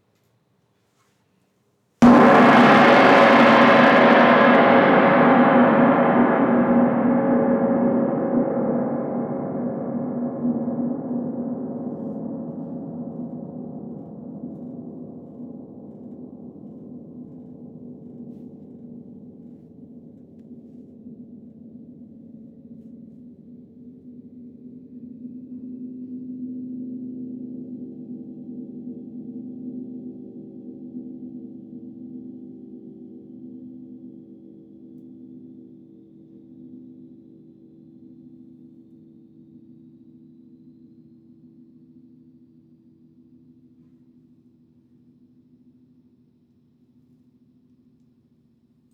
très_fort.wav